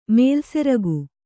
♪ mēlseragu